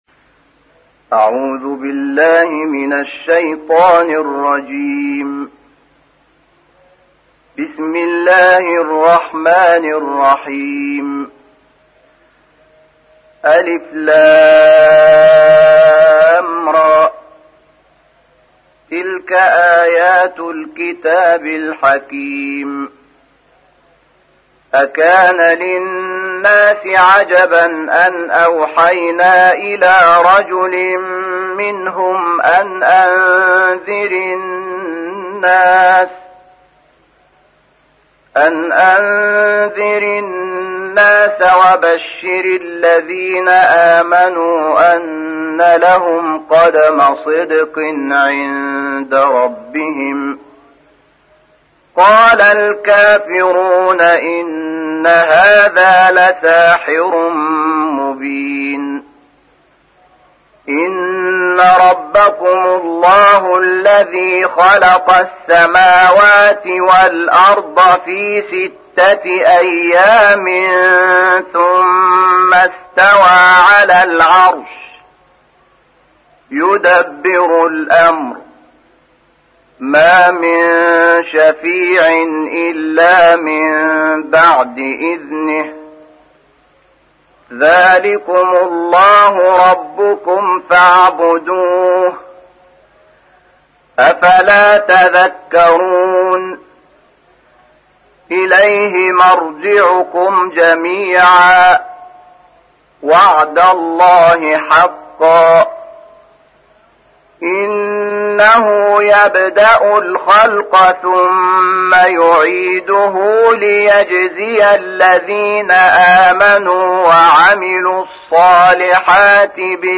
تحميل : 10. سورة يونس / القارئ شحات محمد انور / القرآن الكريم / موقع يا حسين